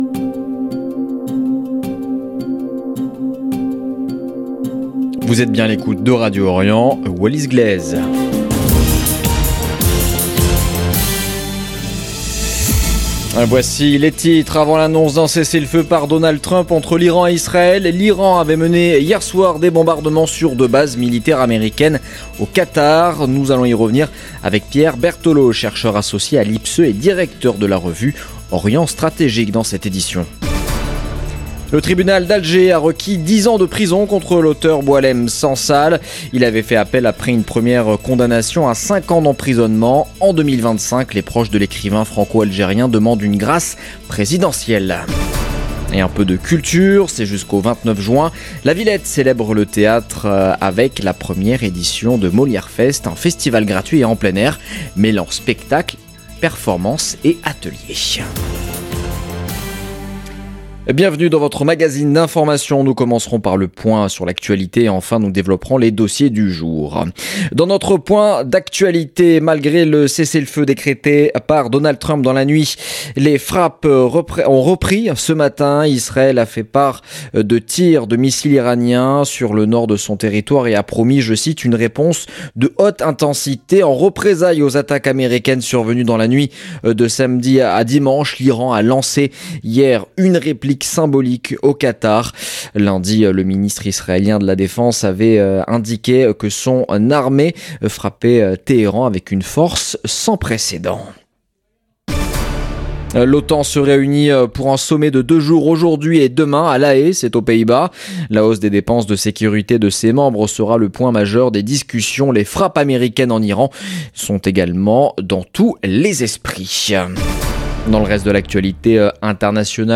Magazine de l'information de 17H00 du 24 juin 2025